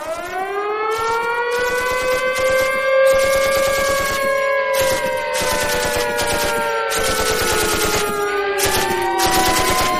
Efectos de sonido
Un tono para tu movil lleno de sirenas y disparos.